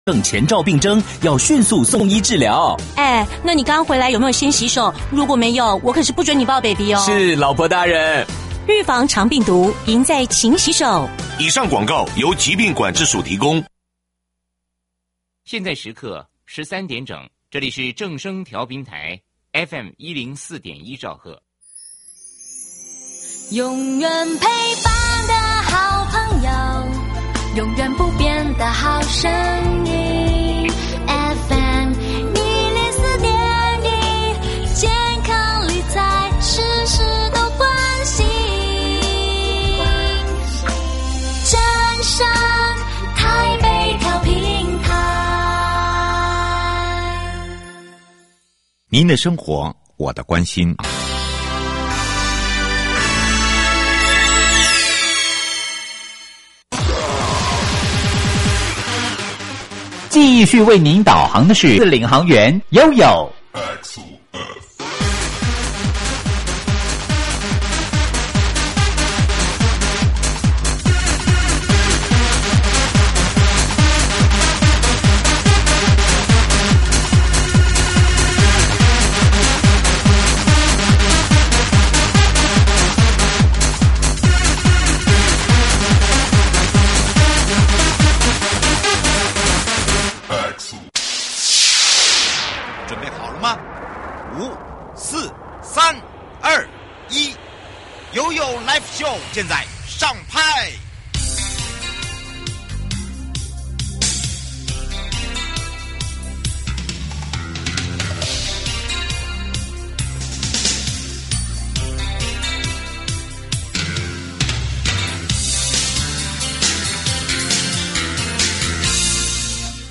受訪者： 營建你我他 快樂平安行~七嘴八舌講清楚~樂活街道自在同行! 台南市人本通行環境、道路規劃、交通安全~